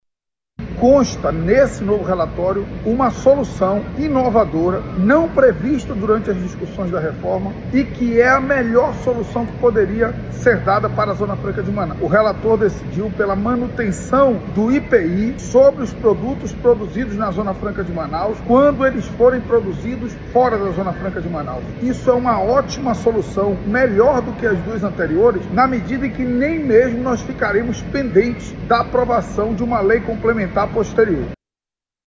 Contudo, a manutenção do Imposto sobre Produtos Industrializados (IPI) para produtos fabricados fora da Zona Franca de Manaus iguais aos produzidos no Estado se mostrou mais simples, como explica o advogado Marcelo Ramos, que é ex-deputado federal.